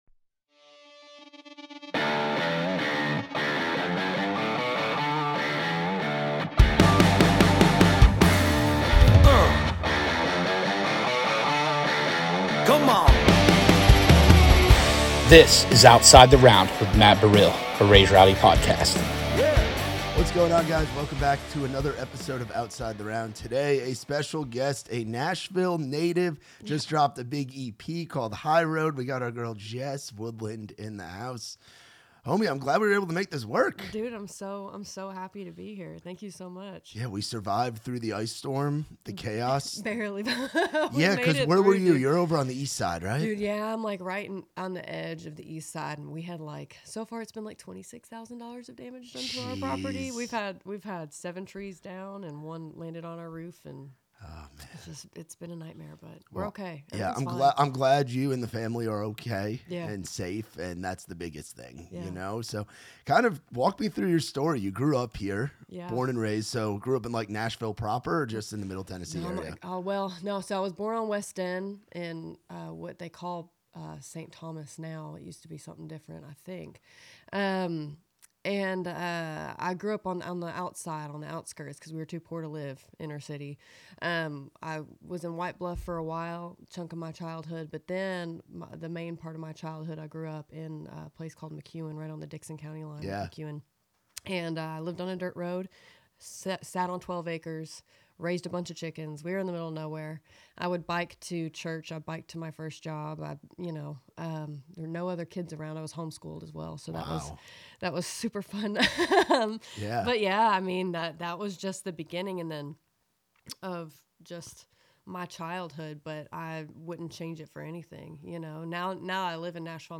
The conversation also explores building a fanbase through social media, navigating the highs and lows of being an independent artist, and the importance of self-worth, community, and resilience.